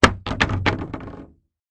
fallingpotatoes.ogg